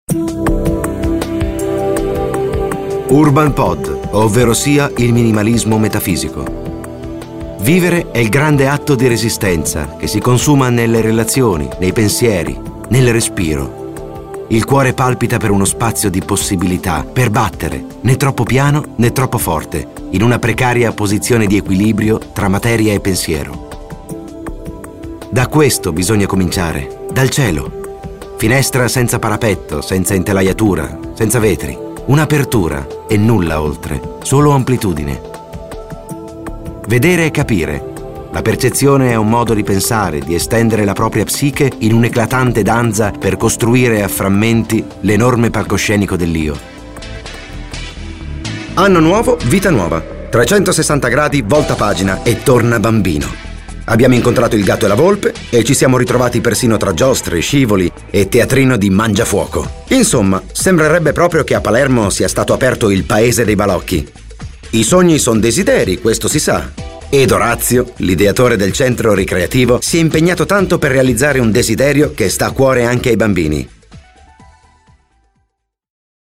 voce calda, dinamica con spiccate doti interpretative
Sprechprobe: eLearning (Muttersprache):